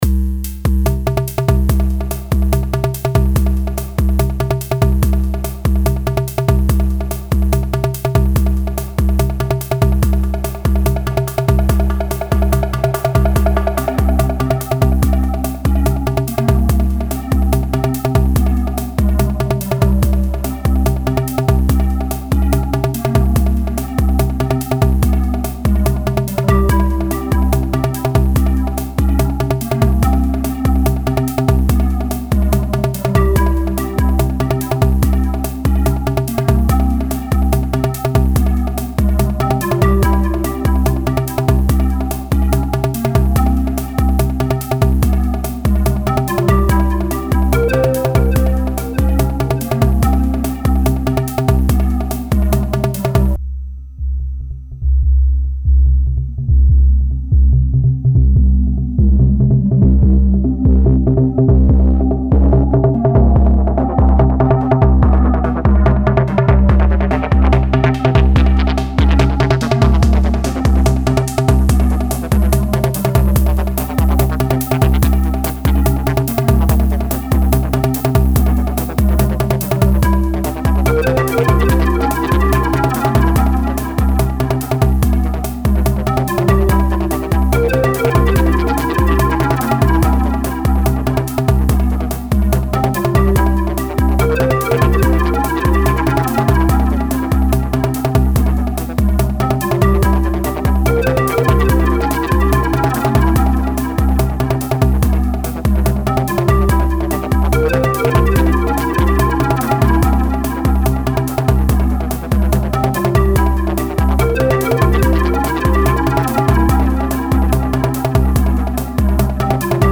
Two patterns, 8 tracks.
First pattern is the primary one, live mutes and knobs.
The second pattern is a filter transition. I automated filter cutoff and resonance with two LFOs and had to turn the overdrive knob manually.
I recorded ST output directly to Cubase.
p.s. I love that FM hat on the track 8